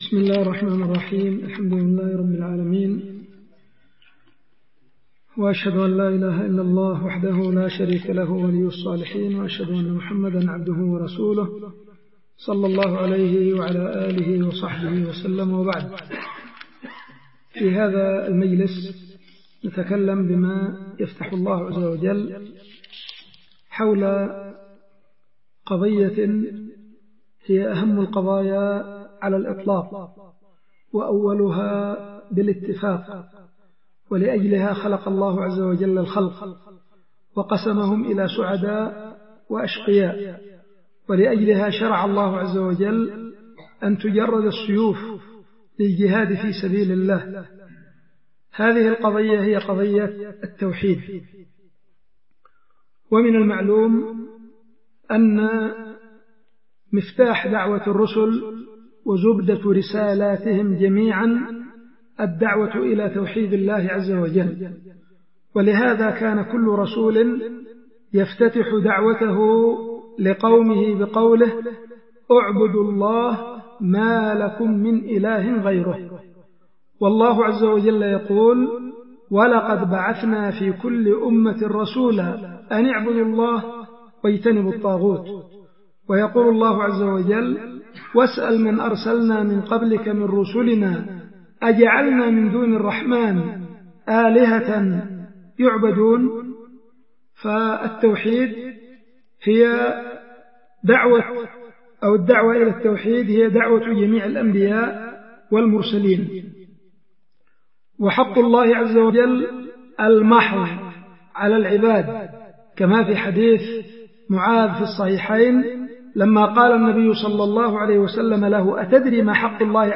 محاضرة
القيت في دار الحديث ببعدان